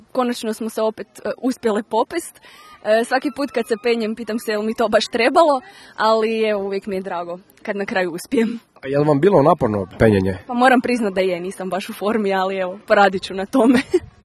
Bili smo na ‘Japu’ i snimili dojmove posjetitelja